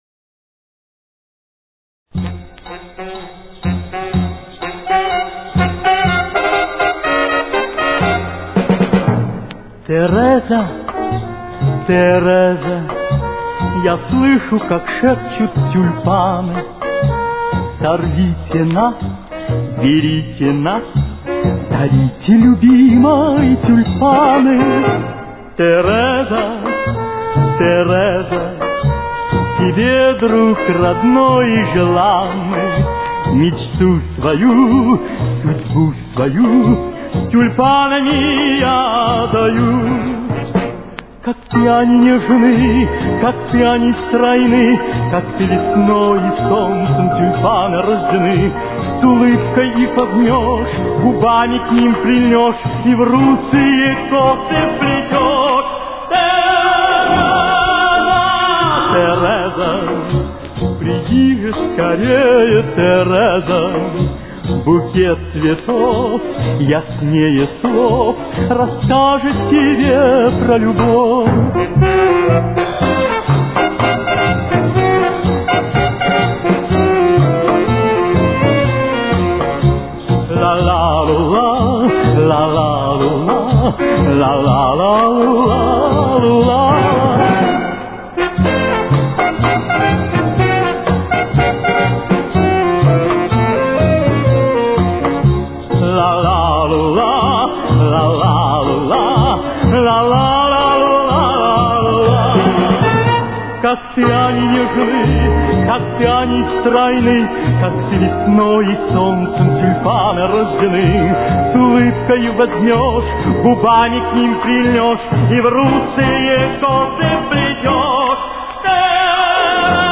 Темп: 125.